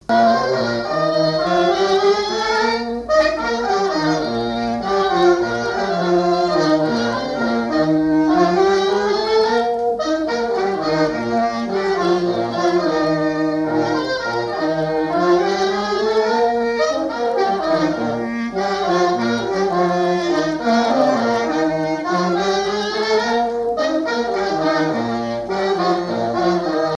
trompette
saxophone
Basse
clarinette
circonstance : fiançaille, noce
Ensemble de marches de noces
Pièce musicale inédite